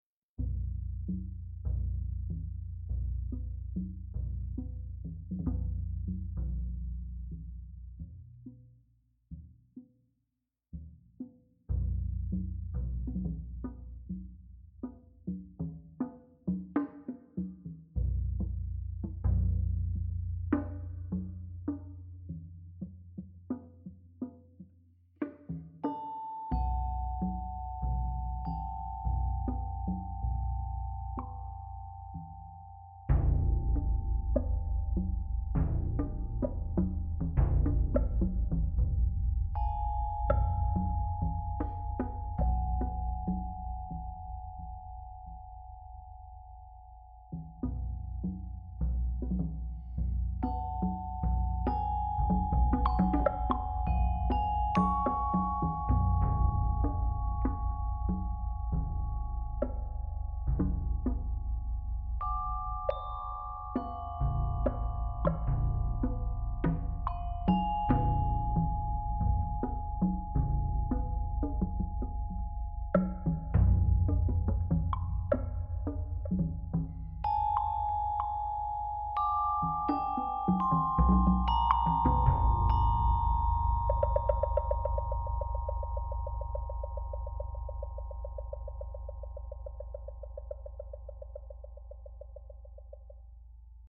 2023 at Warren Lecture Hall Studio A, La Jolla, CA